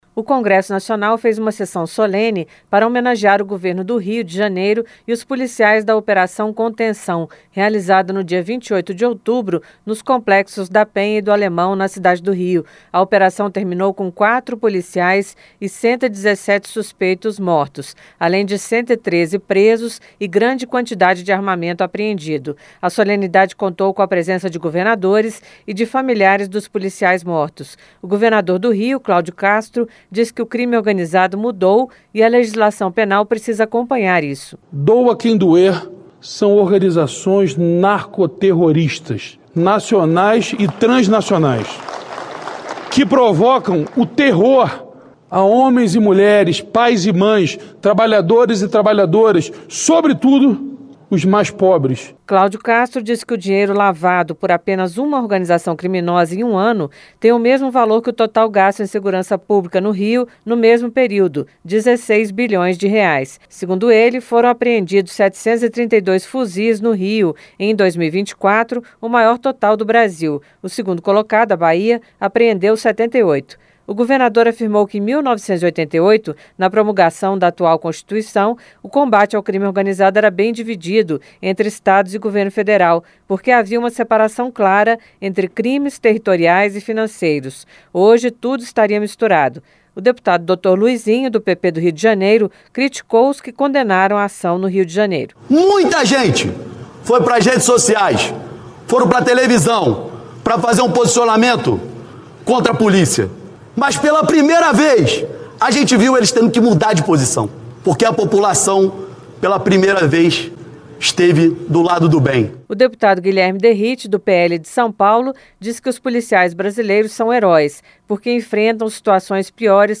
Sessão Solene do Congresso, no Plenário do Senado
• Áudio da matéria